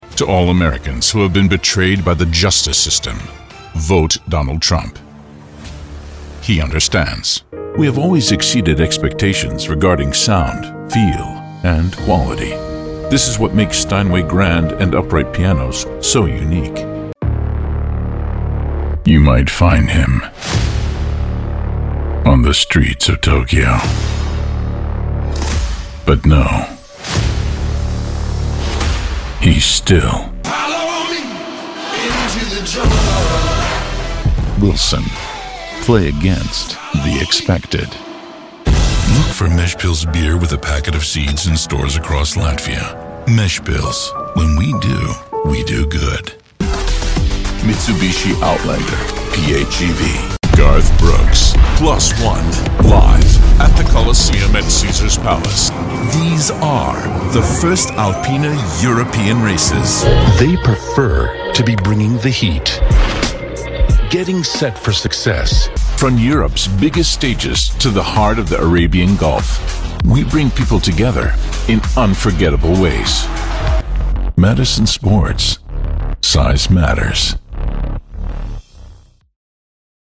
English (British)
Adult (30-50) | Older Sound (50+)